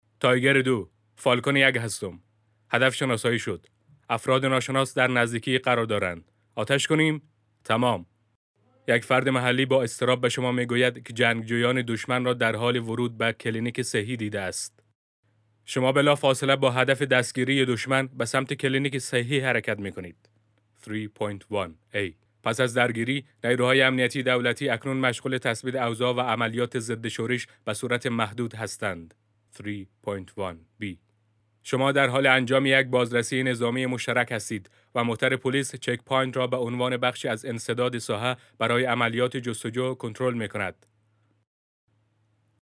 Male
Adult
Game
Dubbing-